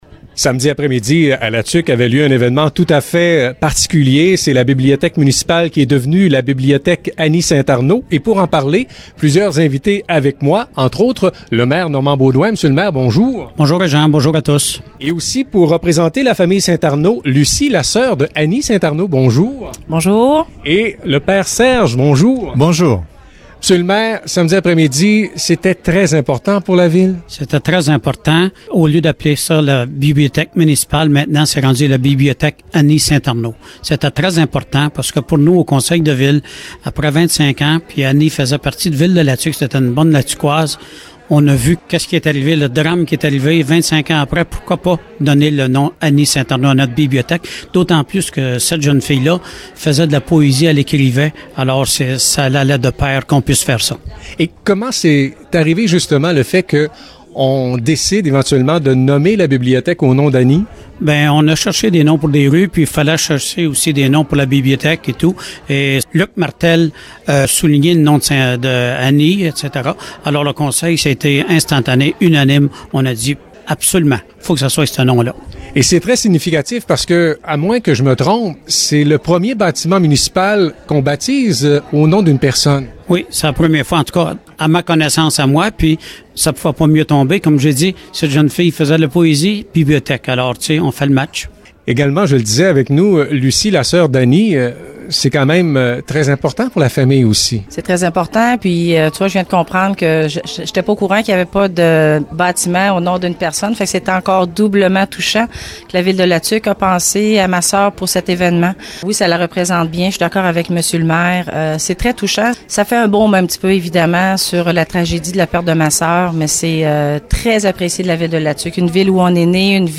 Émission radiophonique complète sur les ondes de CFLM (6 minutes)